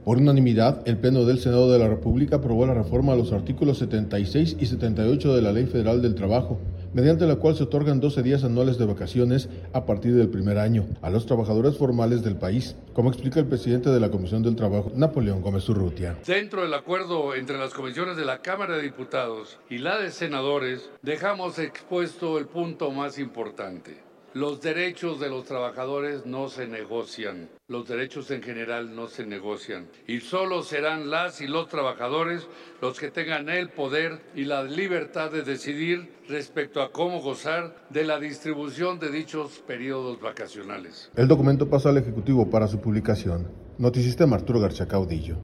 Por unanimidad, el pleno del Senado de la República aprobó la reforma a los artículos 76 y 78 de la Ley Federal del Trabajo, mediante la cual se otorgan 12 días anuales de vacaciones, a partir del primer año, a los trabajadores formales del país, como explica el presidente de la Comisión del Trabajo, Napoleón Gómez Urrutia.